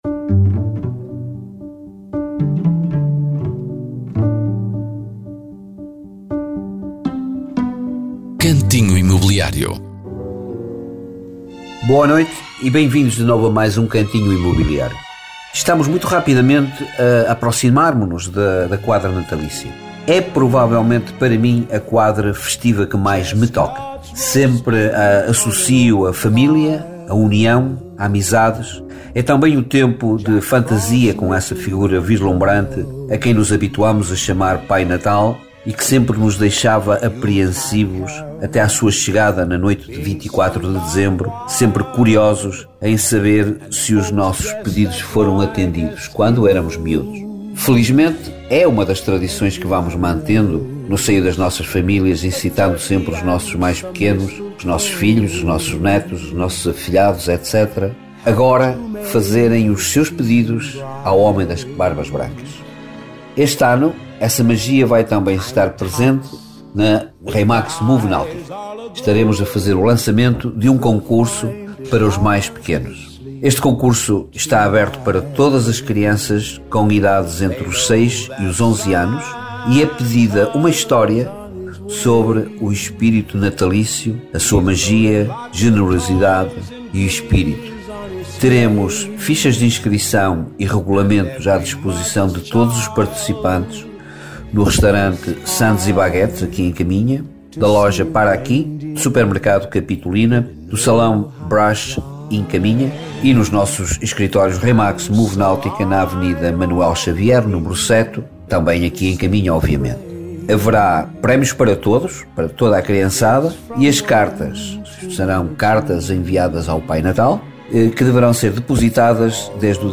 Cantinho Imobiliário é uma rubrica semanal da Rádio Caminha sobre o mercado imobiliário.